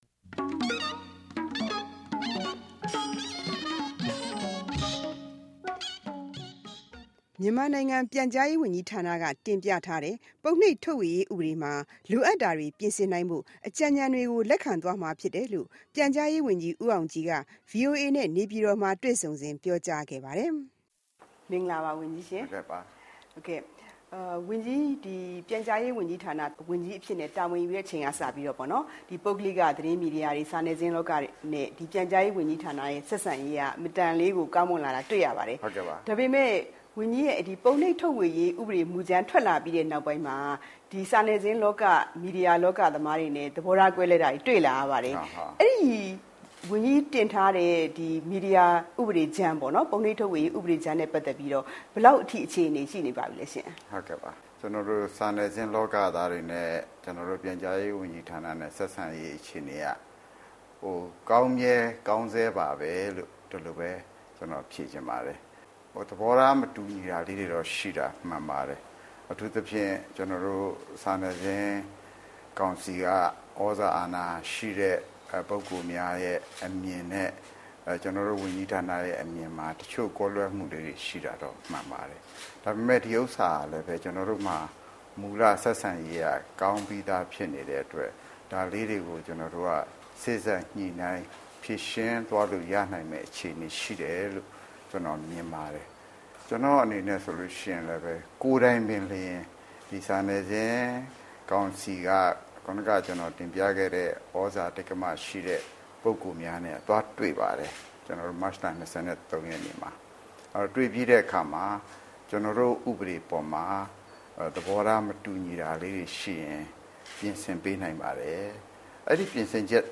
ပြန်ကြားရေးဝန်ကြီးနဲ့ တွေ့ဆုံမေးမြန်းခန်း